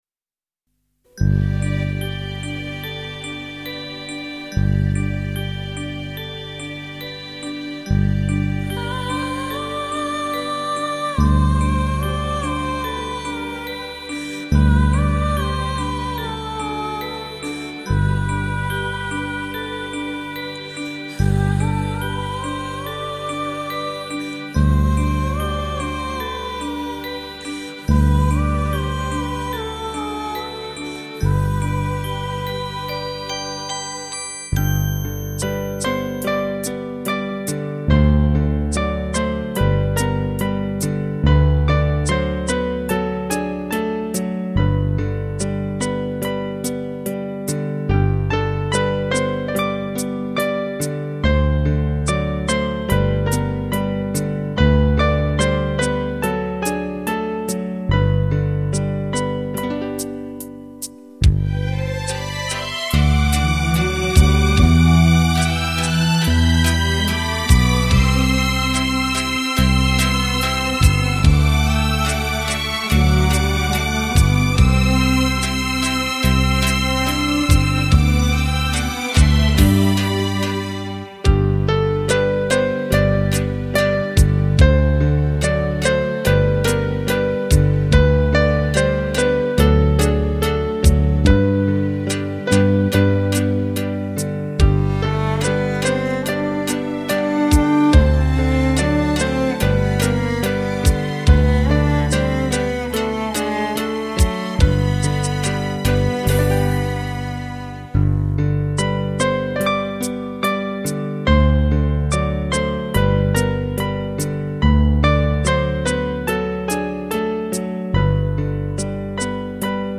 首首精彩，耳不暇给，音乐意境多姿多彩，美不胜收！